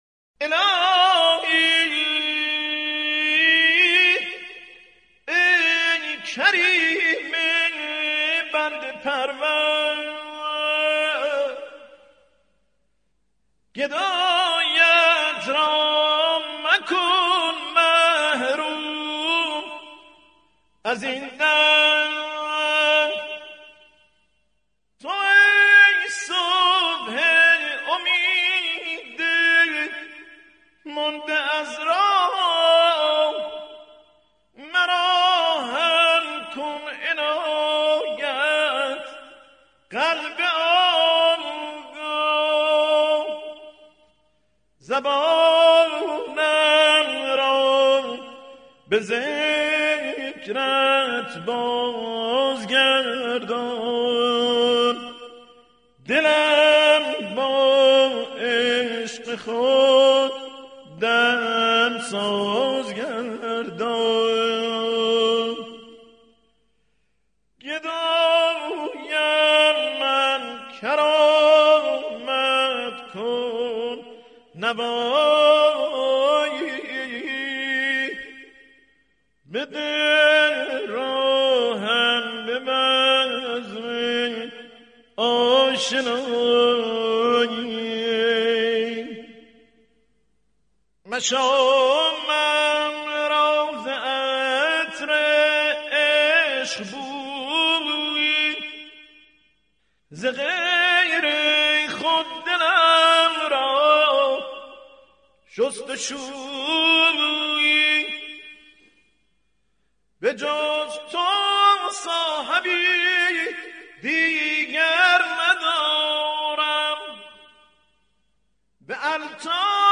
• موسیقی ملل